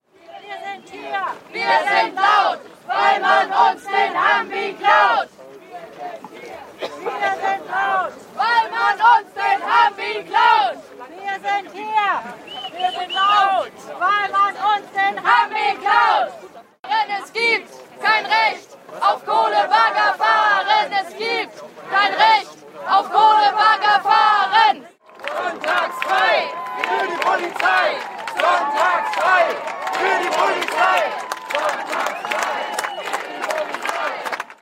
Demonstration am Hambacher Forst (Audio 7/8)
Schlachtrufe (zusammengeschnitten) (Audio 7/8) [MP3]